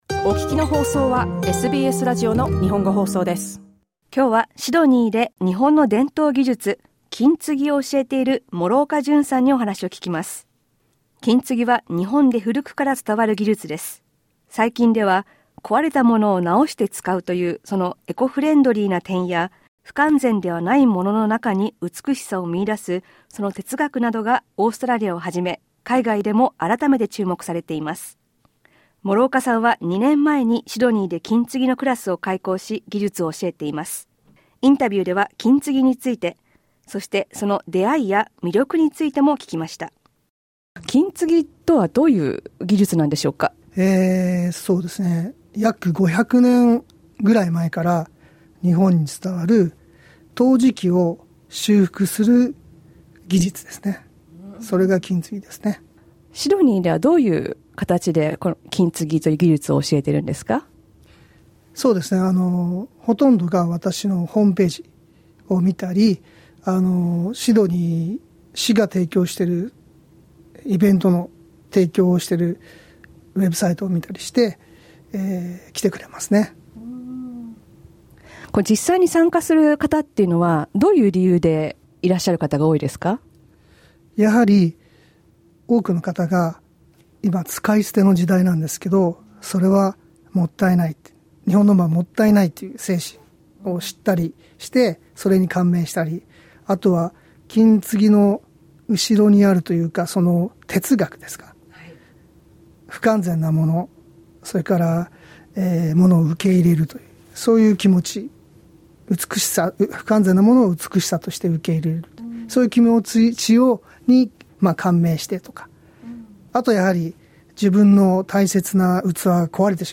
2019年に放送されたインタビューをもう一度どうぞ。